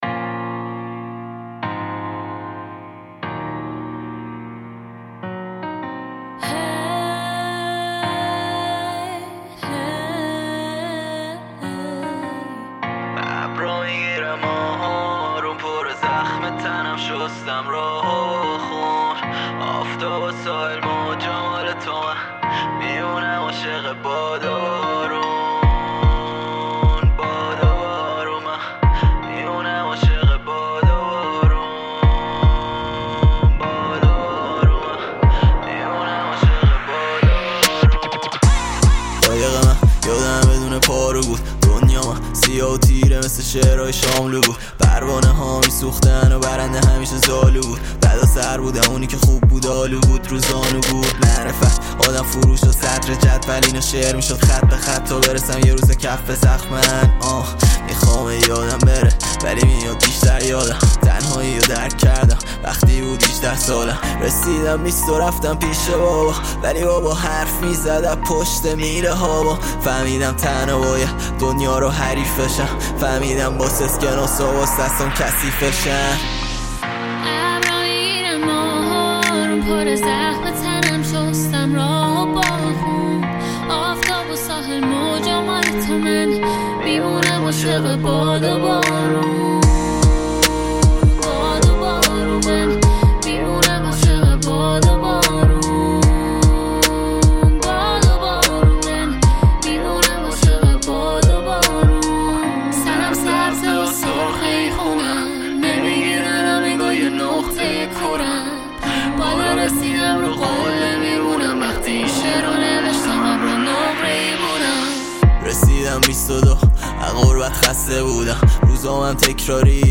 هیپ هاپ
رپ فارسی
صدای خش دارقشنگ